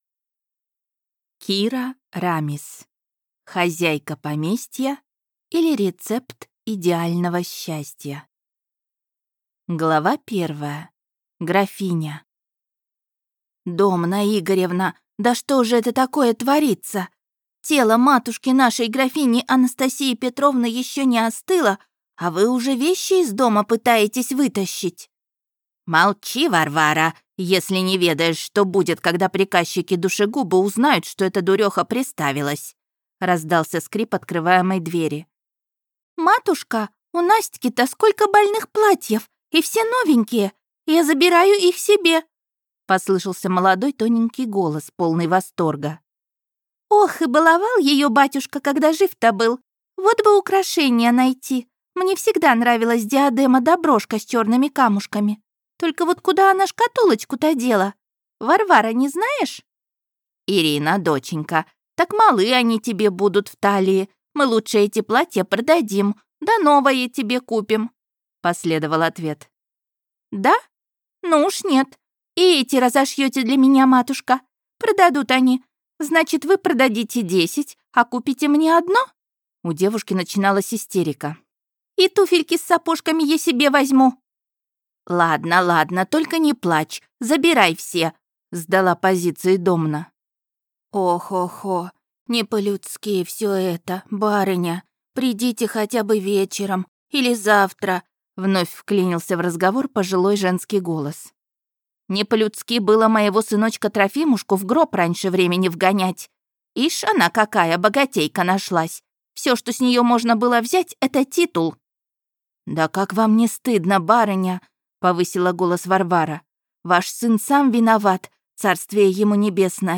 Аудиокнига Хозяйка поместья, или Рецепт идеального счастья | Библиотека аудиокниг